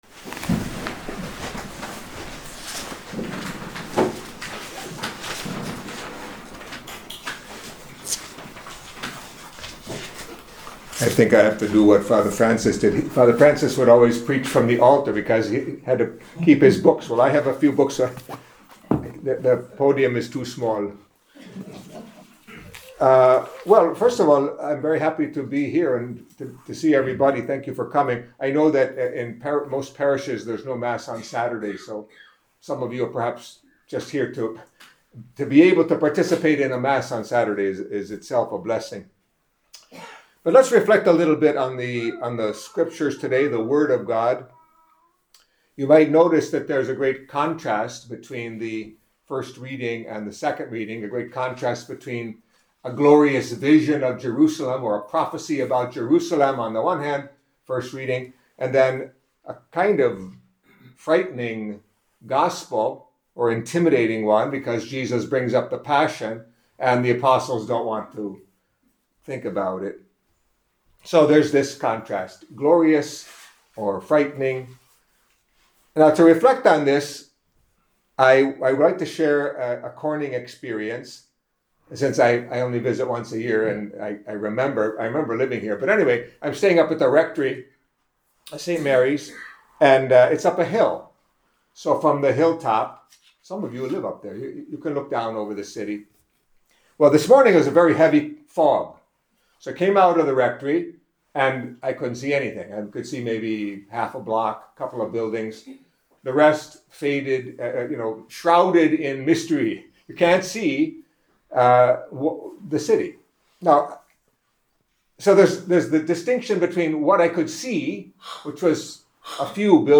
Catholic Mass homily for Saturday of the Twenty-Fifth Week in Ordinary Time